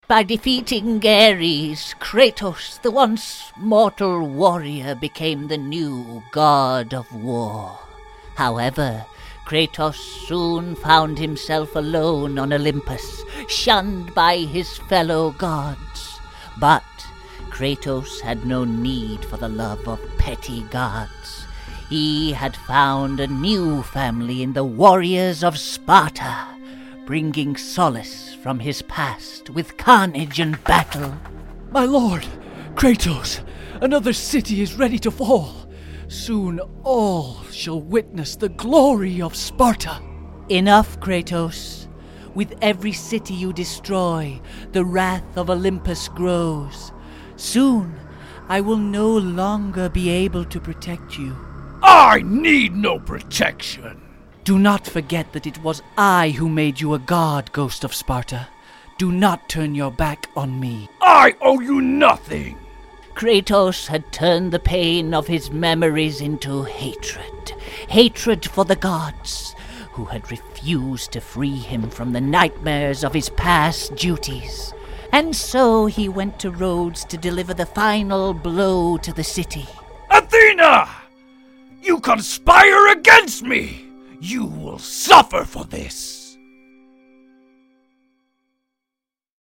A little - God Of War Practice script :)